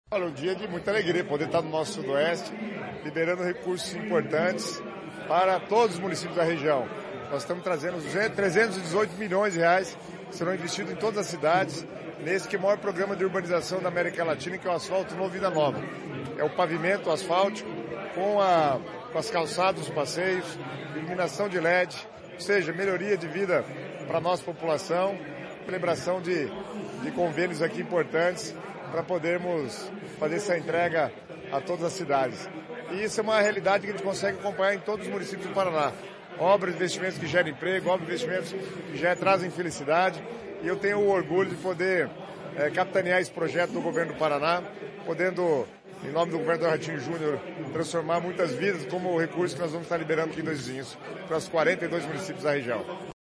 Sonora do secretário de Estado das Cidades, Guto Silva, sobre a assinatura de convênio do programa Asfalto Novo, Vida Nova